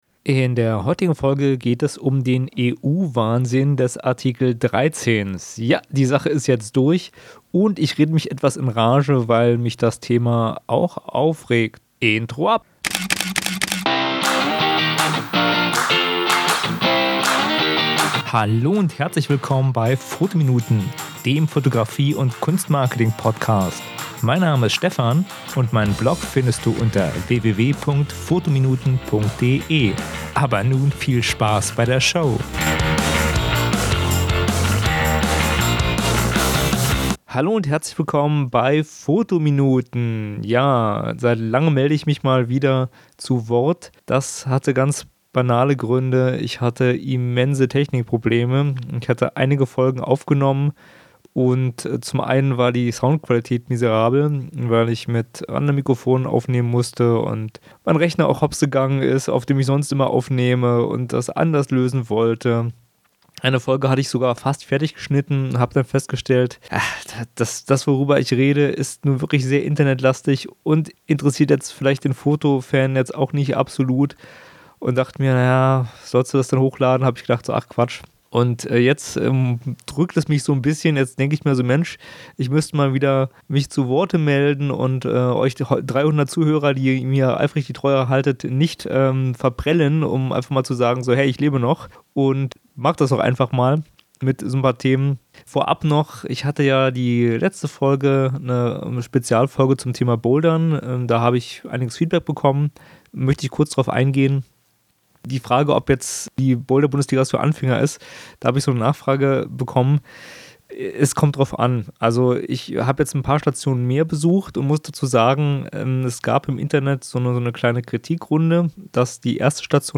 Deswegen geht es in dieser Folge um das Thema "Uploadfilter", Artikel 13 und "Lobbygesetz". Ich rede mich ein bischen in Rage. Außerdem führe ich aus, was Google davon hat (Spoileralert: Das Thema wird Google nicht schaden).